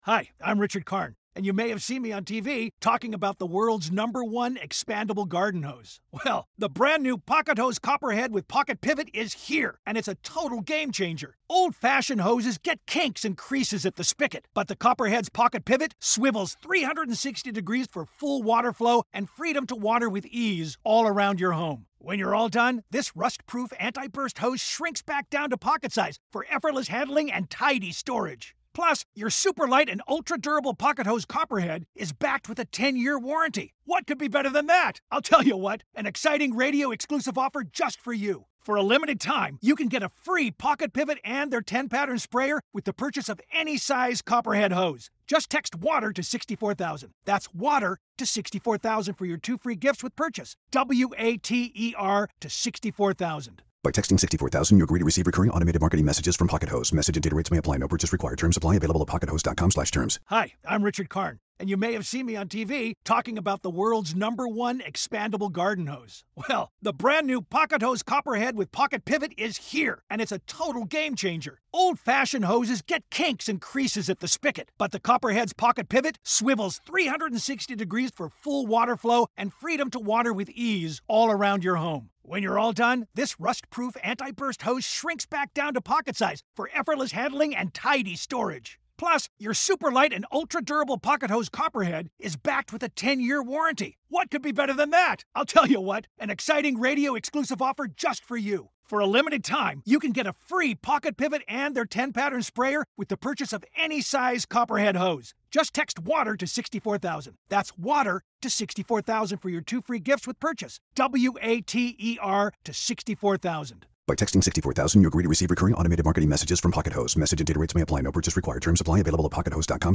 On this episode of Investing in Real Estate, I'm taking a swing at three listener questions on HELOCs, non-recourse financing, and what type of loan to get for a duplex.